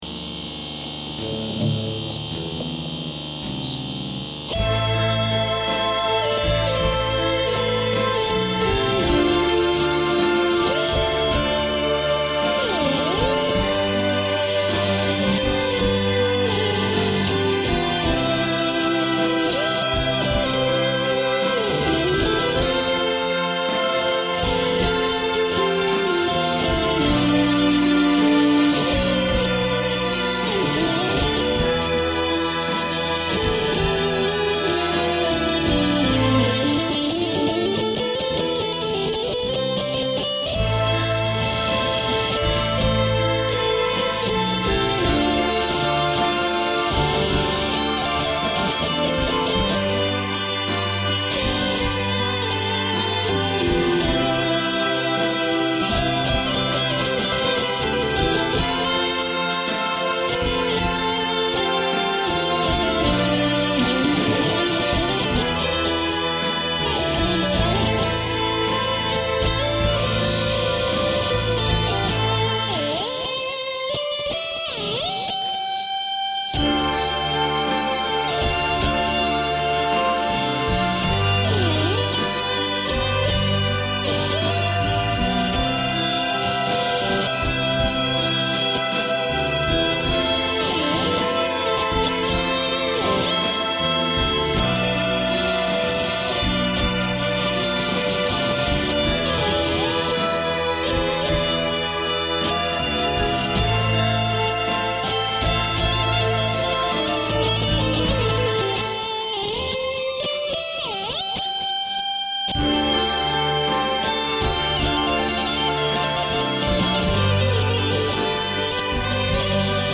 Solos sur backing tracks.
troitoirs parisiens (1 prise) 1999 [ ha ha ha. le début est horrible :) ]